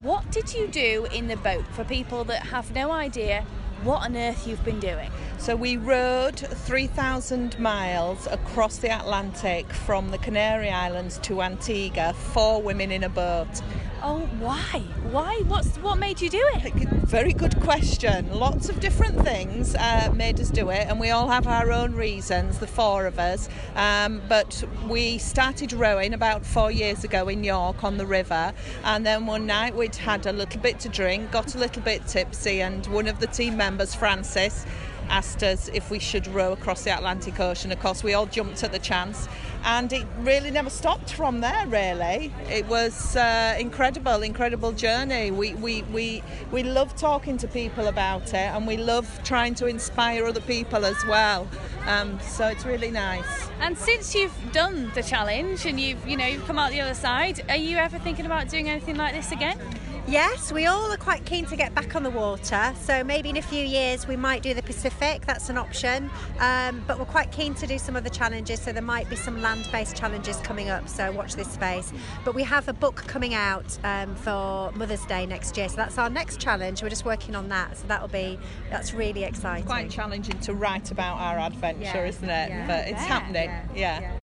Great Yorkshire Show 2016: Yorkshire Rows